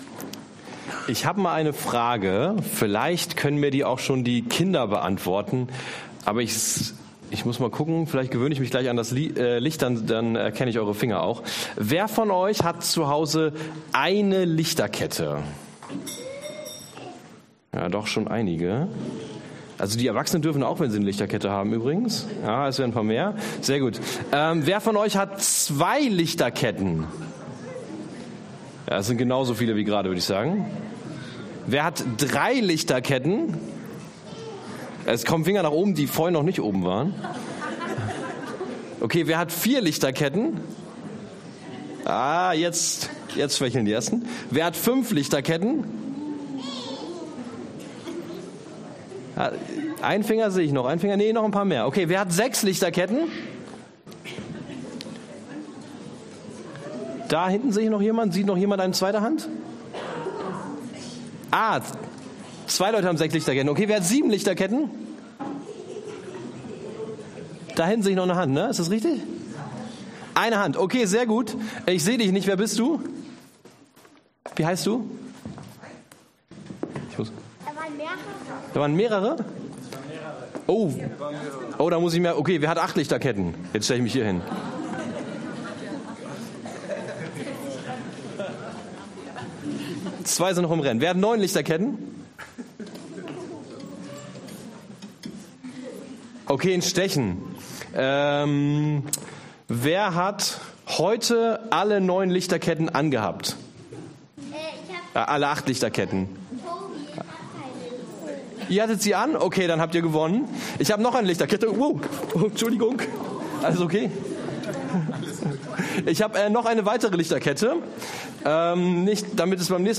Passage: Lukas 1,26-35 und 2,6-14 Dienstart: Predigt